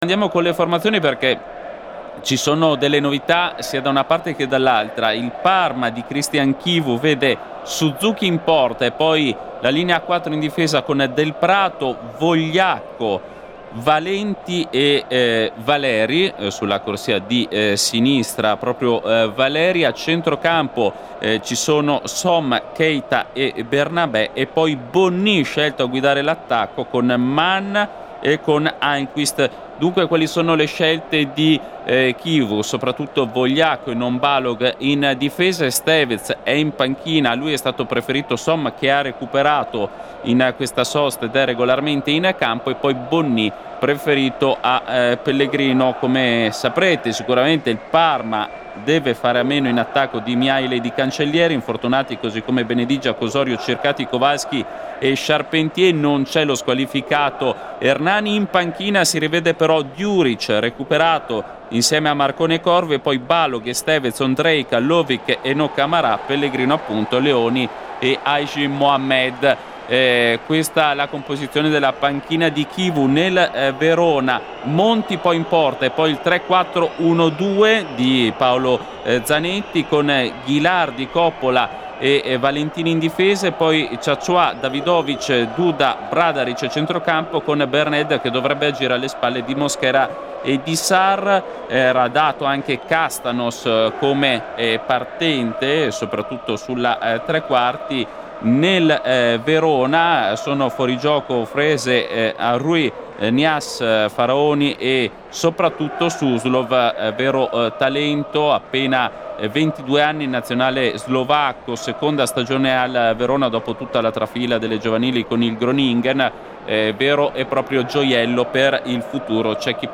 Verona-Parma al Bentegodi.
Radiocronaca